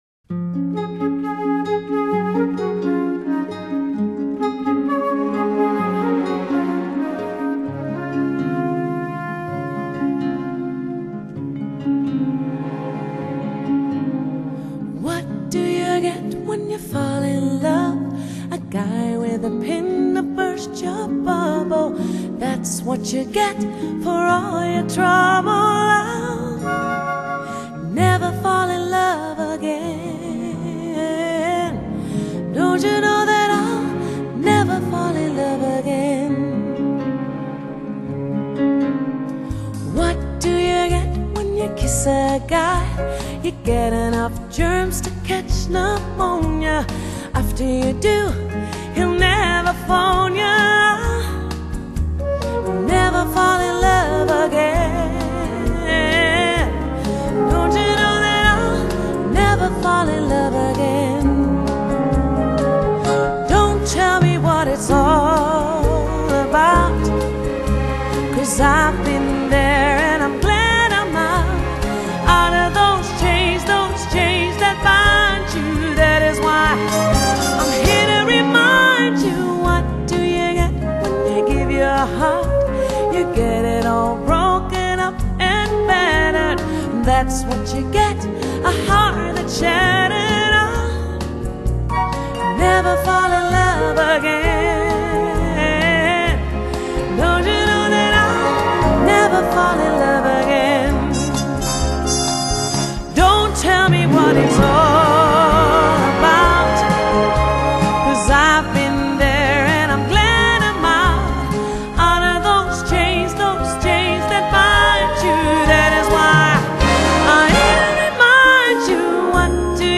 【荷蘭爵士首席女伶】